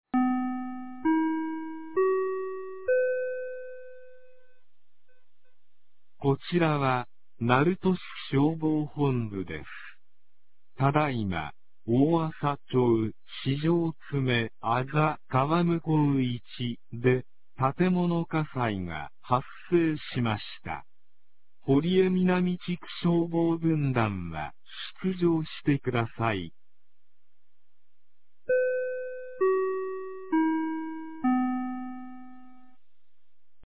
2026年04月10日 07時20分に、鳴門市より大麻町-市場、大麻町-牛屋島、大麻町-松村へ放送がありました。